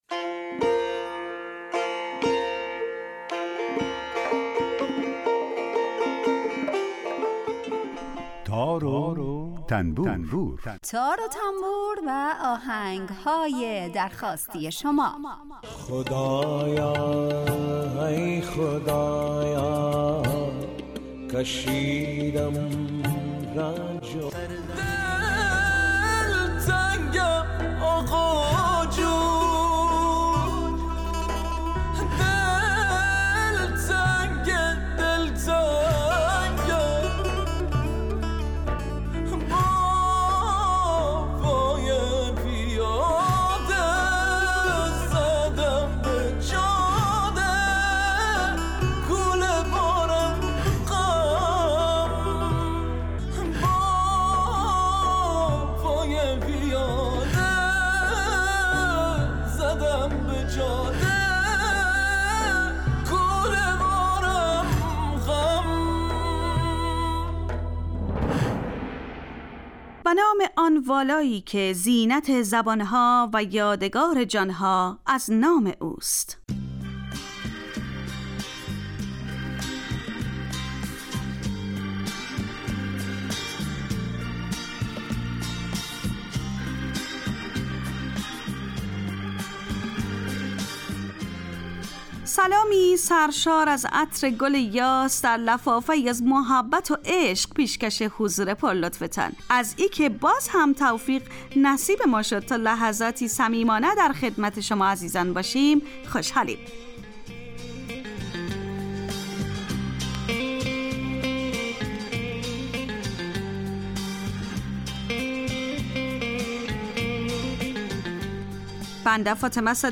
برنامه تار و تنبور هر روز از رادیو دری به مدت 40 دقیقه برنامه ای با آهنگ های درخواستی شنونده ها کار از گروه اجتماعی رادیو دری.
در این برنامه هر یه آیتم به نام در کوچه باغ موسیقی گنجانده شده که به معرفی مختصر ساز ها و آلات موسیقی می‌پردازیم و یک قطعه بی کلام درباره همون ساز هم نشر میکنیم